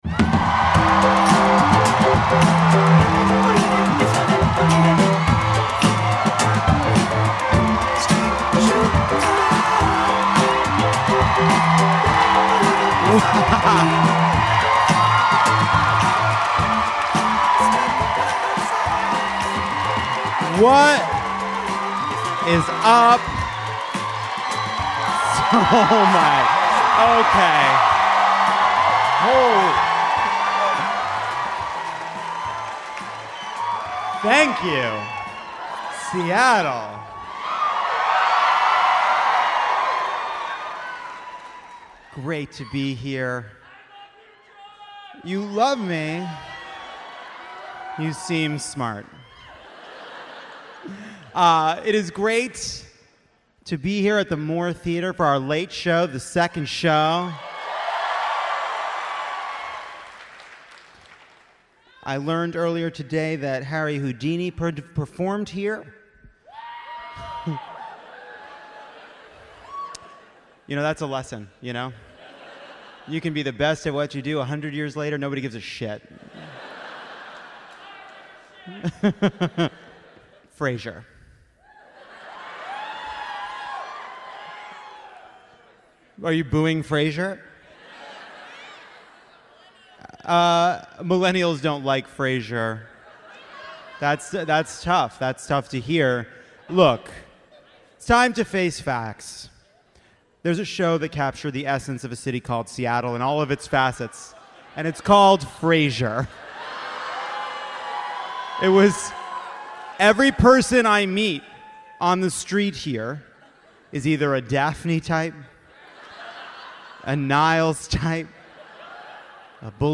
Trump puts an immigration proposal on the table. GOP finance chair Steve Wynn is accused of misconduct. And Sean Hannity struggles to serve master. Live from the Moore Theatre in Seattle, Jon is joined by Ijeoma Oluo, Lindy West, and Akilah Hughes to break down the week’s news and reference Frasier because people from Seattle love Frasier.